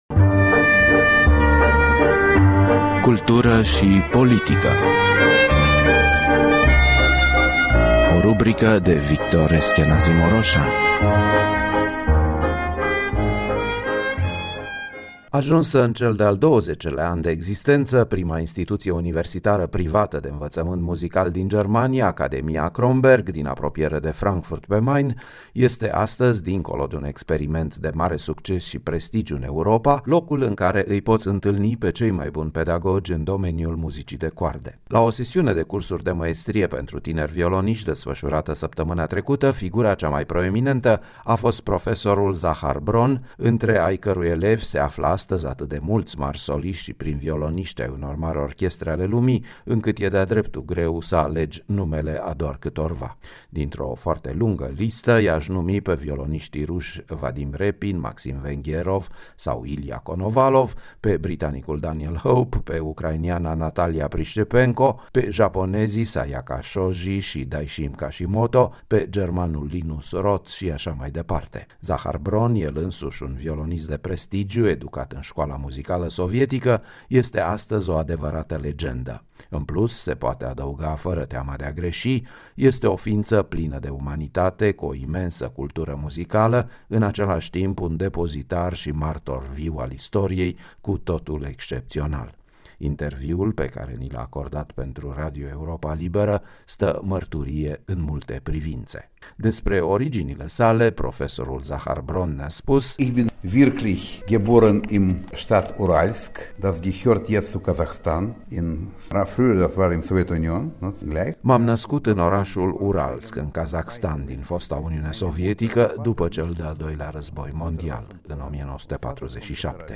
Cultură și politică: Un interviu cu marele pedagog al viorii Zakhar Bron